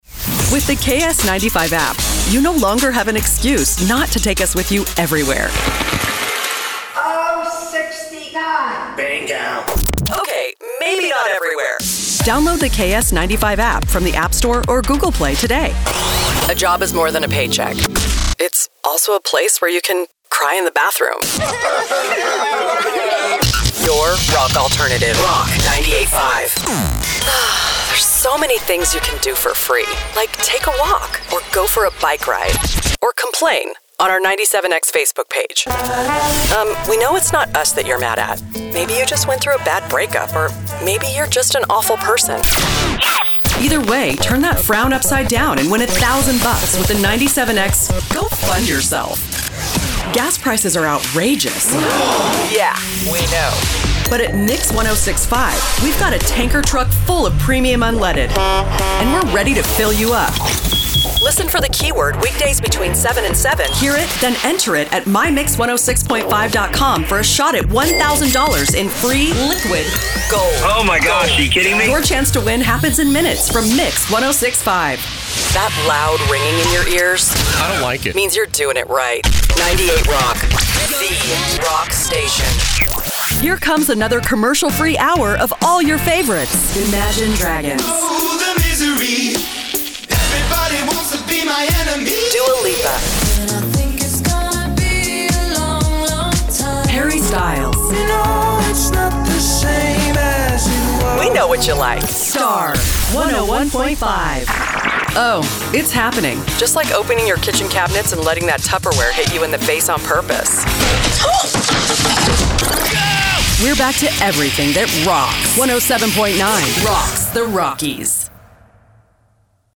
Wise, authentic, commanding, warm, young, friendly, sincere, and inclusive.
Neutral American